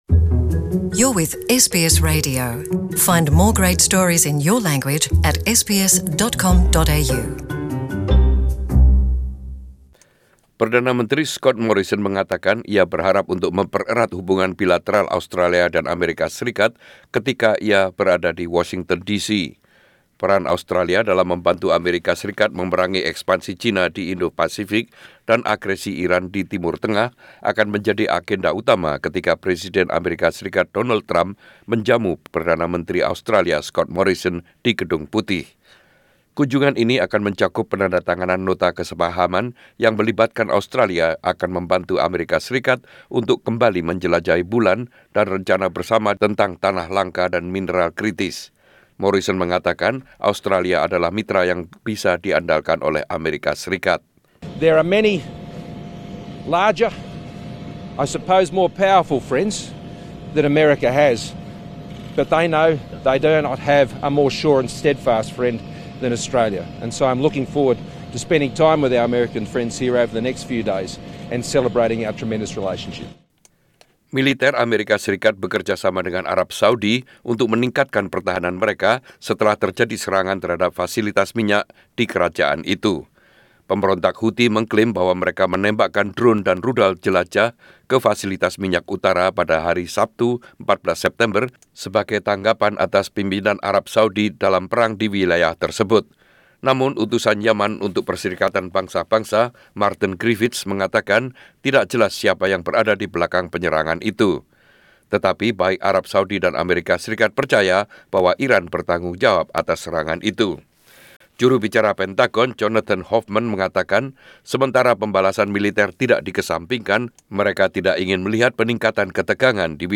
News Bulletin - Friday 19 Sep 2019